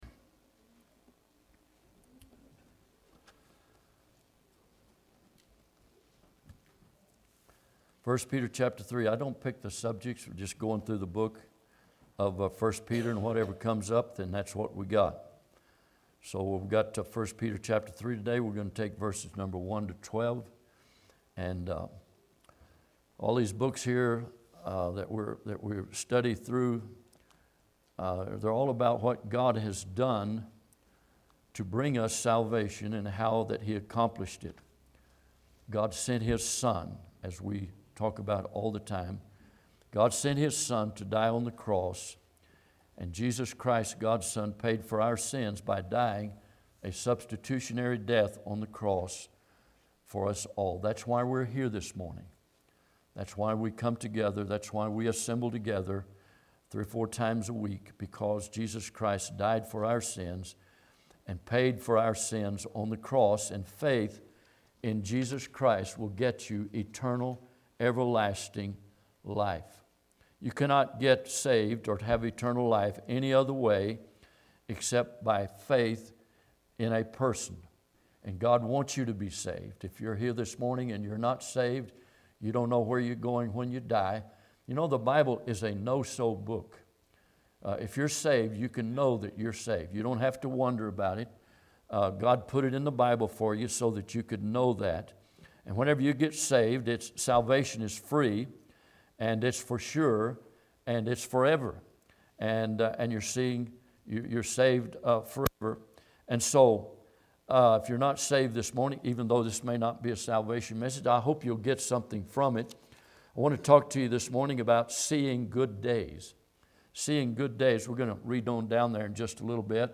I Peter 3:1-10 Service Type: Sunday am Bible Text